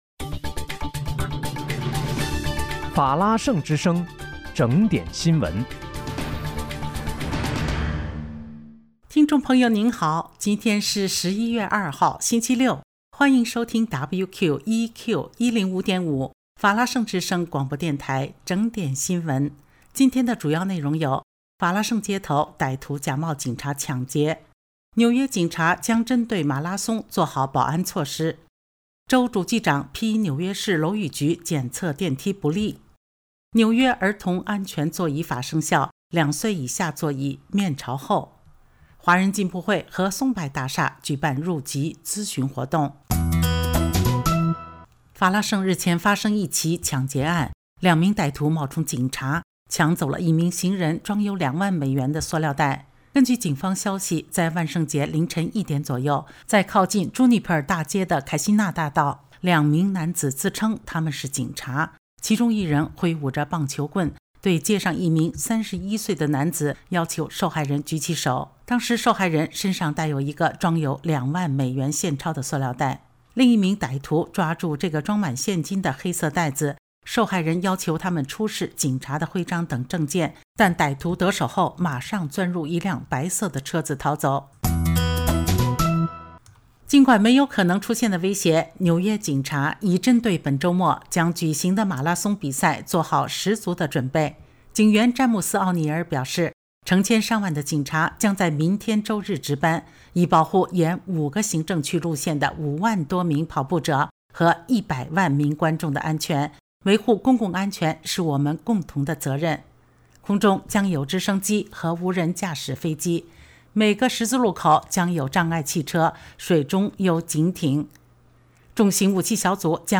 11月2日（星期六）纽约整点新闻